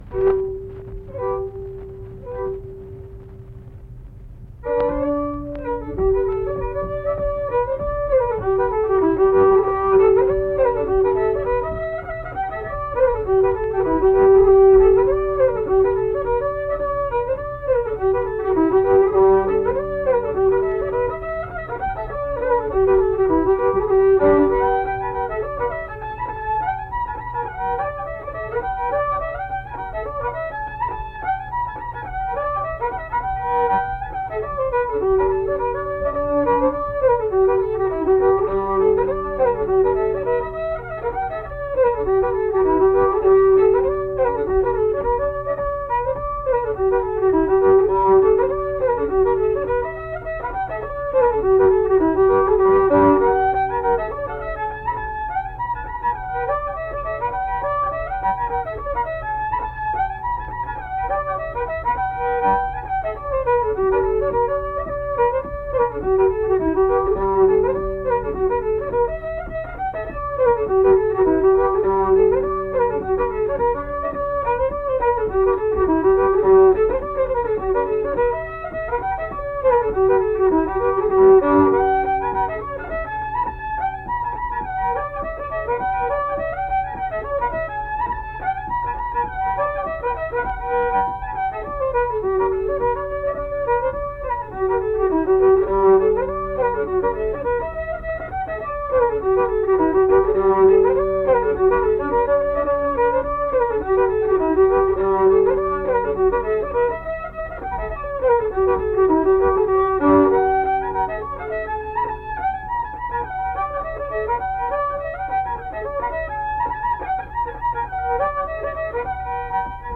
Accompanied guitar and unaccompanied fiddle music performance
Instrumental Music
Fiddle
Mill Point (W. Va.), Pocahontas County (W. Va.)